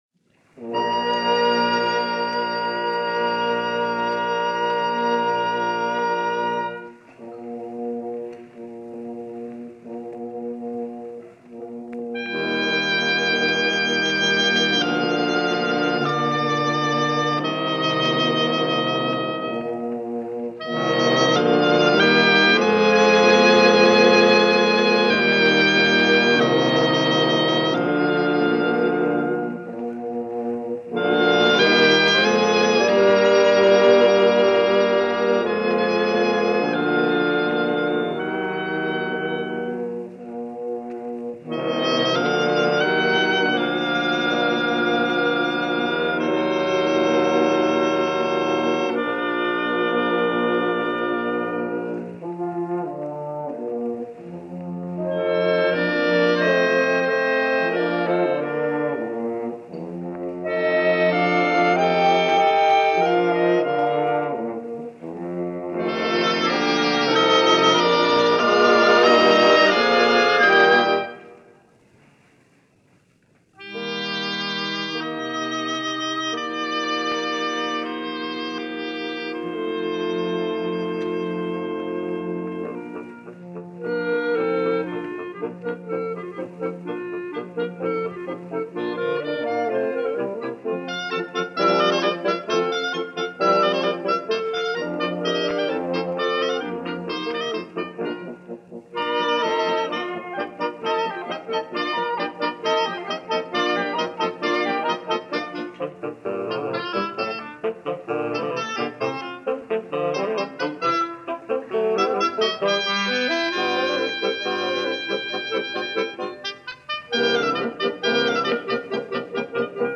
studio session
This Petite Symphonie is a Nonet for Winds, first performed in Paris in 1885.